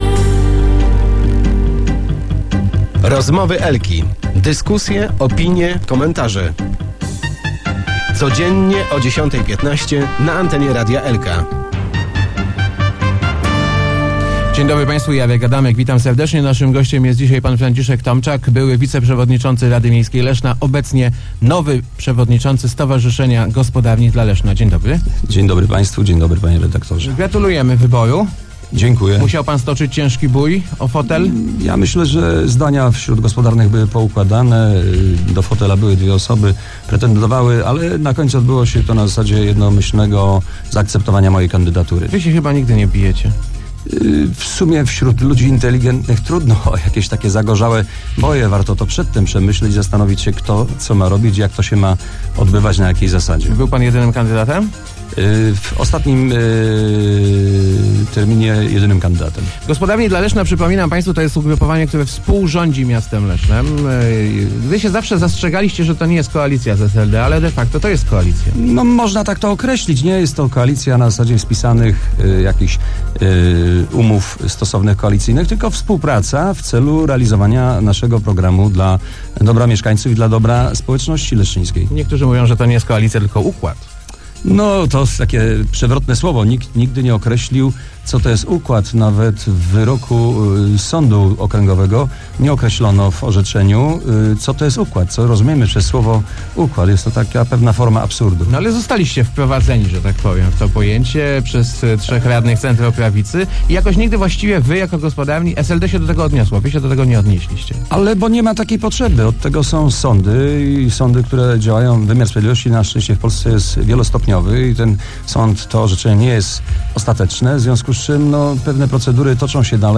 W Rozmowach Elki przekonywa� on, �e Gospodarni nie s� tylko „przystawk�” do rz�dz�cego w mie�cie SLD. Mamy swój program, który realizujemy – mówi�.